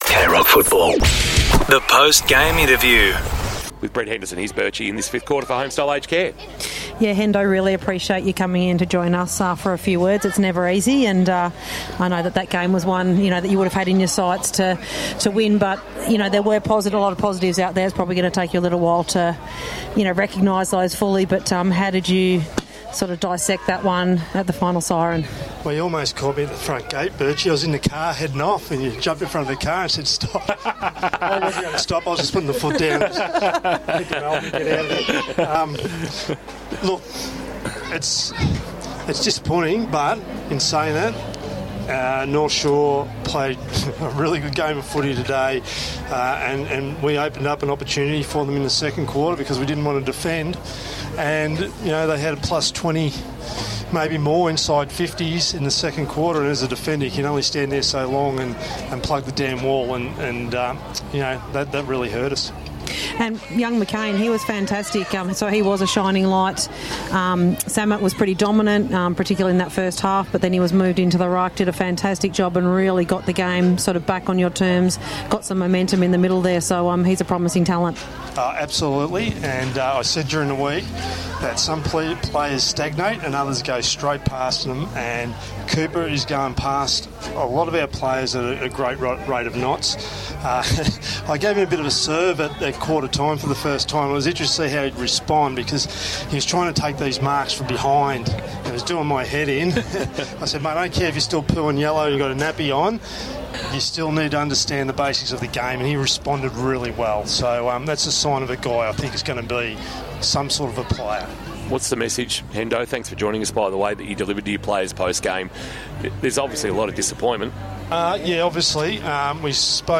2025 - GFNL - Round 9 - Lara vs. North Shore: Post-match interview